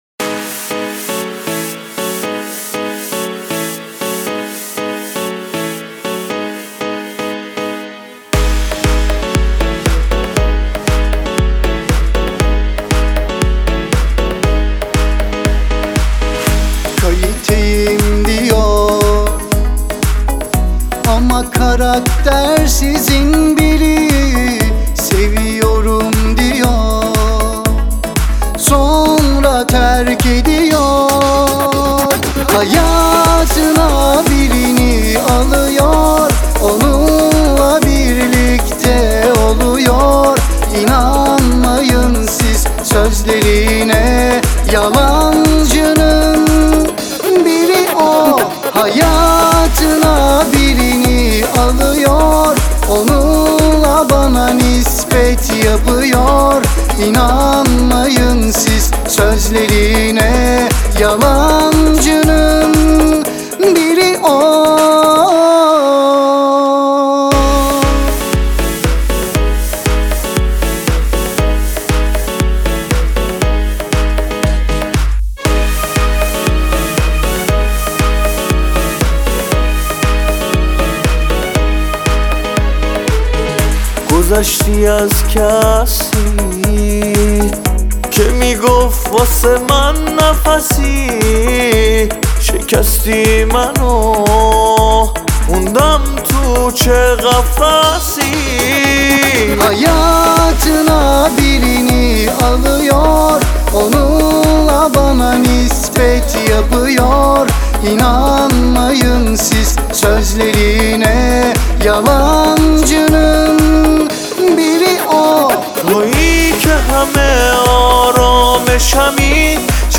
دانلود اهنگ پاپ